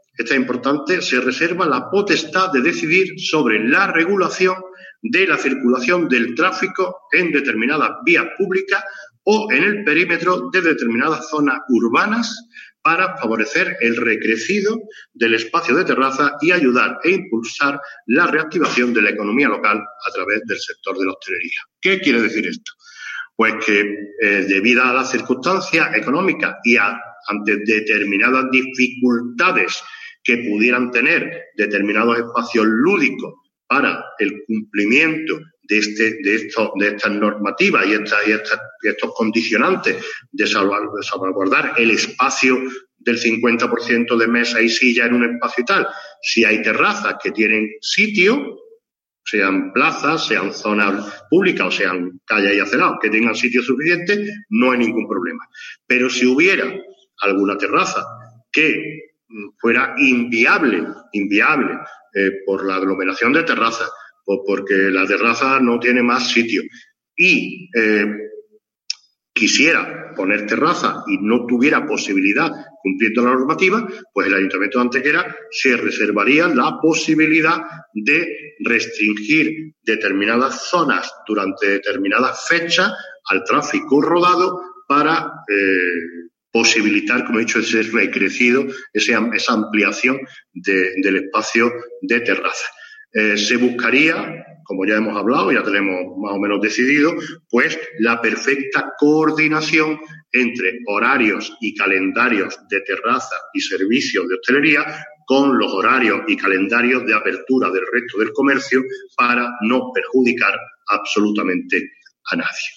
Mención especial durante la rueda de prensa ha requerido este último punto, para el que el Alcalde ha concretado que podría darse el caso de habilitar el cierre al tráfico de determinadas zonas o vías públicas para ampliar tanto el espacio dedicado a terrazas como el paso de peatones, facilitando así un mayor auge de la economía ligada a la hostelería y el tránsito seguro de personas.
Cortes de voz